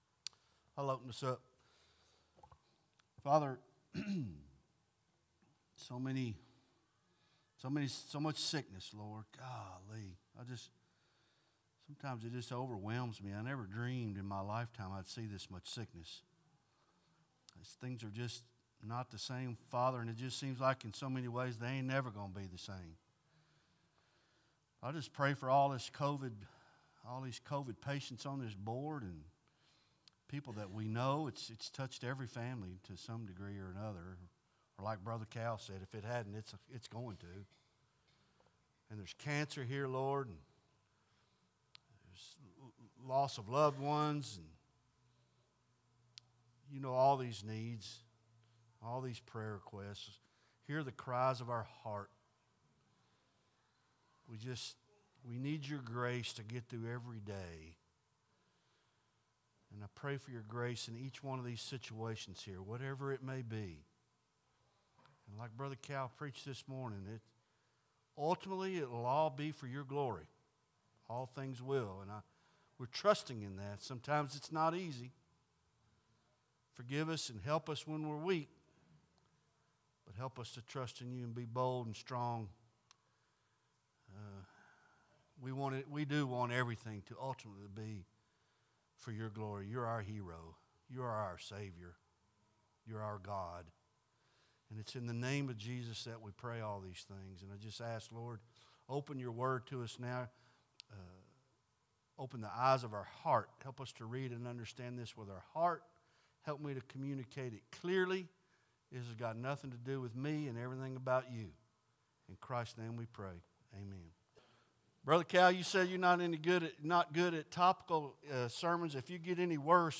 Bible Study 1st Corinthians Lesson 25 Ch 15 Part 6